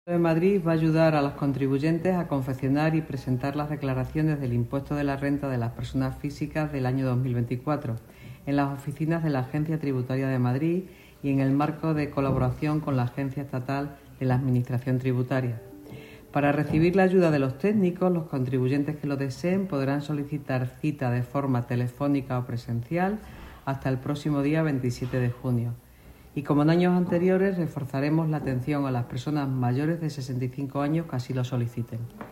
Nueva ventana: La delegada de Economía, Innovación y Hacienda, Engracia Hidalgo: